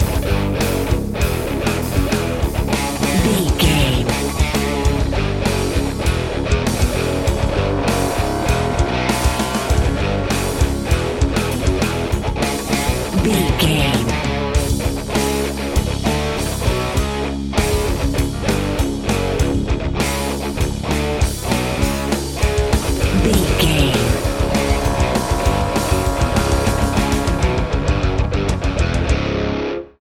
Epic / Action
Fast paced
Aeolian/Minor
C♯
Fast
drums
bass guitar
electric guitar